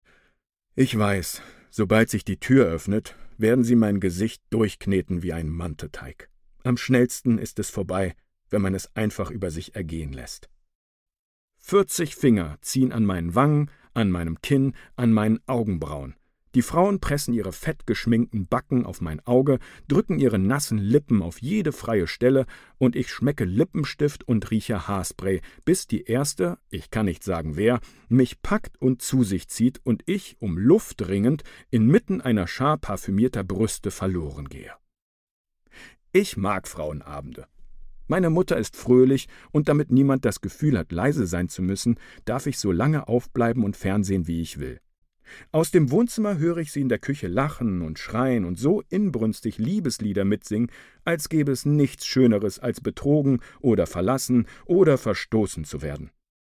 sehr variabel, markant
Mittel plus (35-65)
Audiobook (Hörbuch)